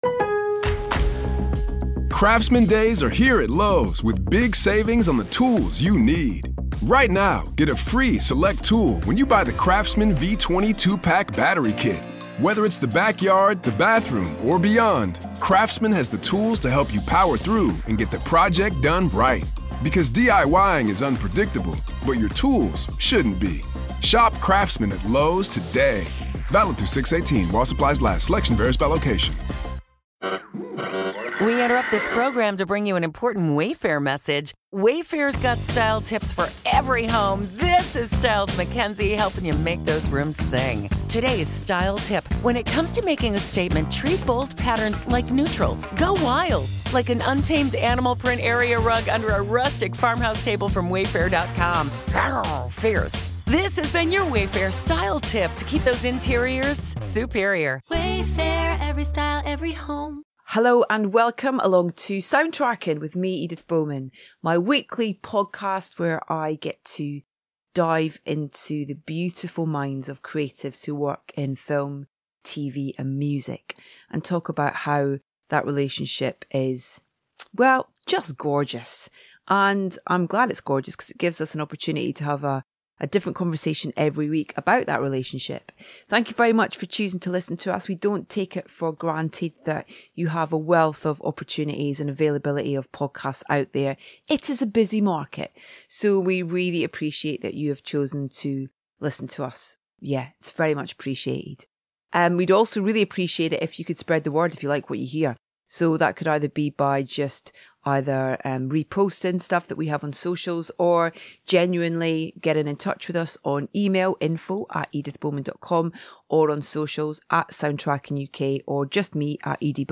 As always, you'll hear extracts of it throughout the chat.